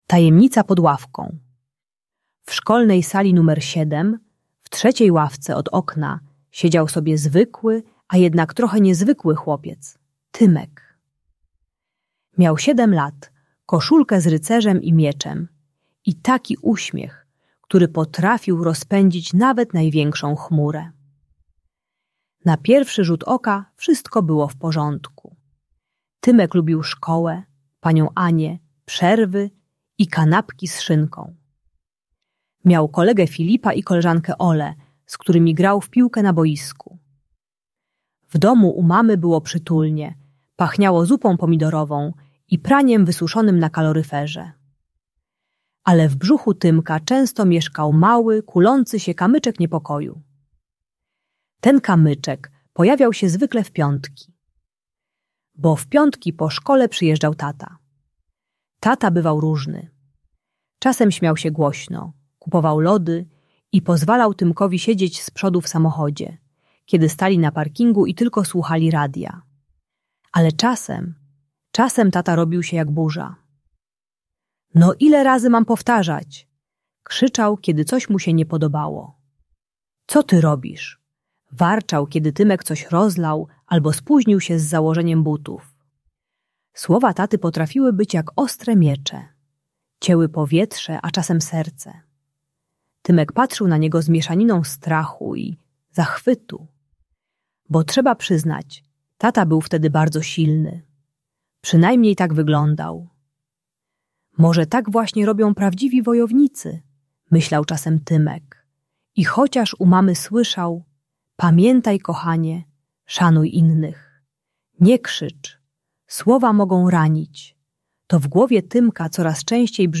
Audiobook dla dzieci o rozwodzie rodziców pomaga zrozumieć trudne emocje gdy tata krzyczy. Uczy techniki "tarczy" - trzech kroków asertywnej komunikacji: STOP, PRAWDA, POMOC. Bajka o radzeniu sobie ze złością i modelowaniu zachowań rodzica.